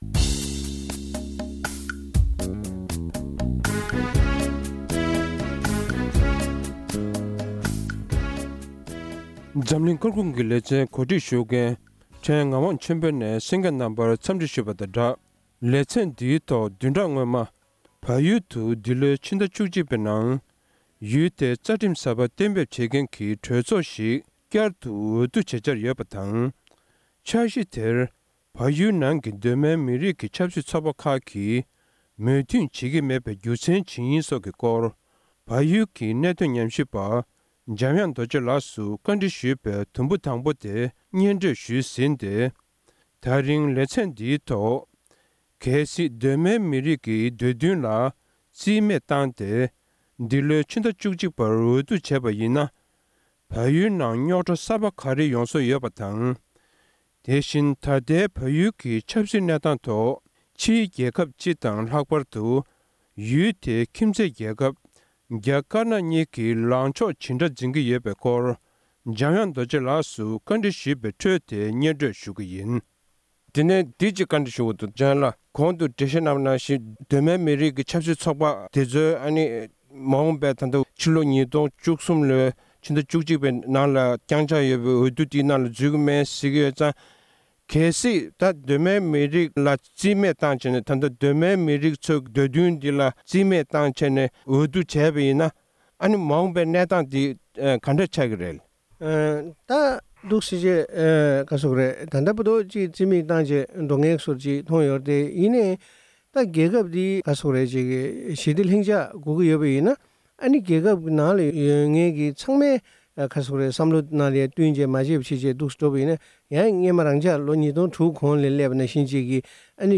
དགོངས་ཚུལ་བཀའ་འདྲི་ཞུས་པའི་དུམ་བུ་གཉིས་པ་དེ།